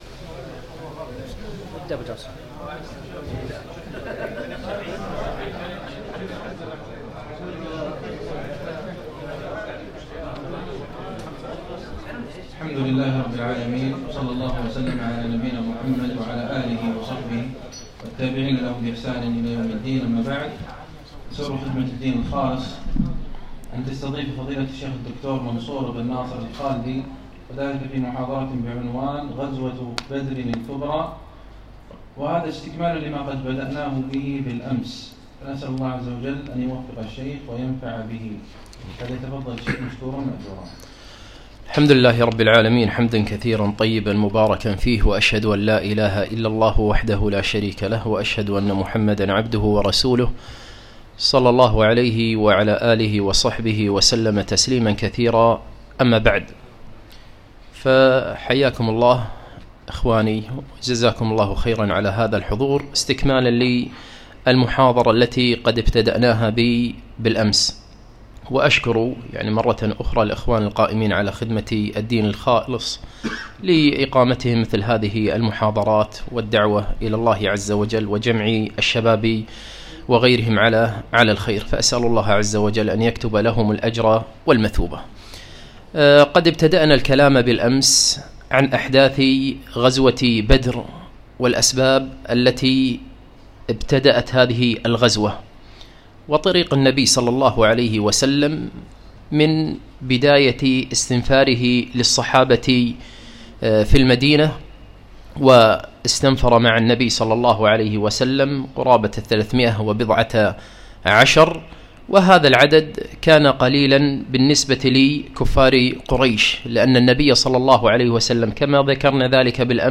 محاضرة - غزوة بدر الكبرى -المجلس الثاني-